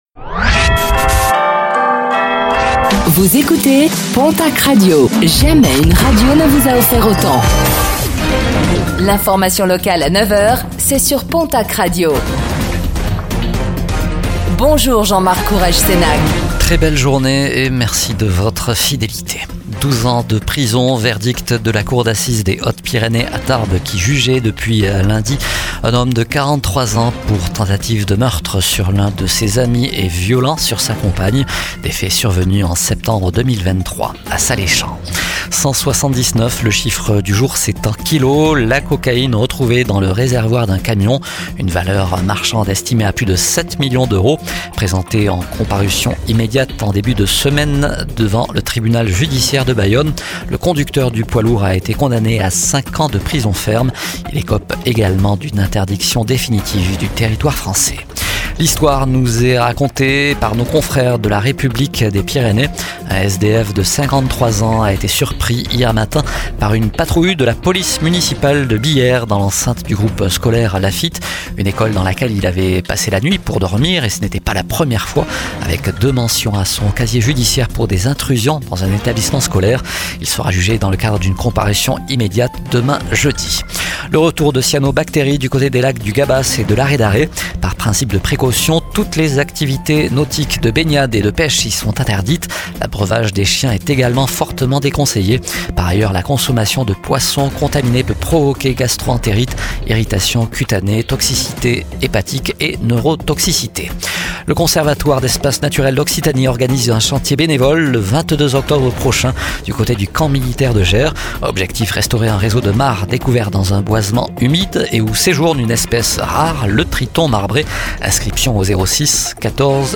Réécoutez le flash d'information locale de ce mercredi 15 octobre 2025